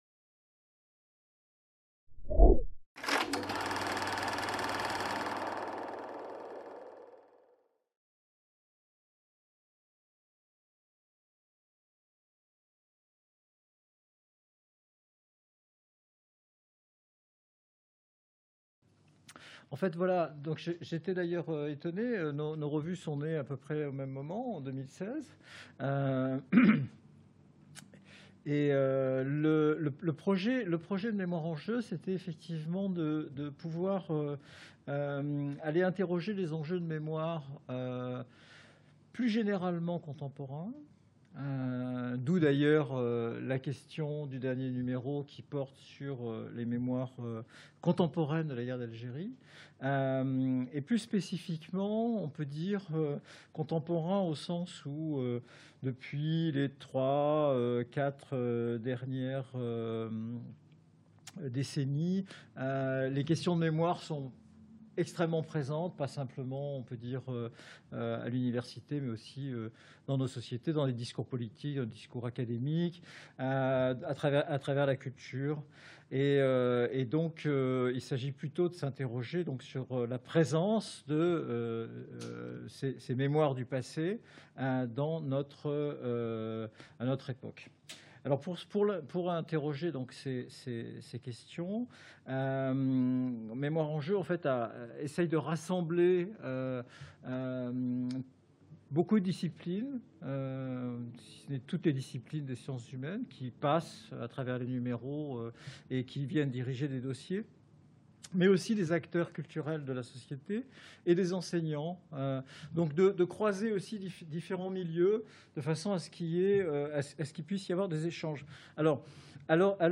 Soirée d'échange entre deux belles revues, "Mémoires en jeu" et "Sensibilité"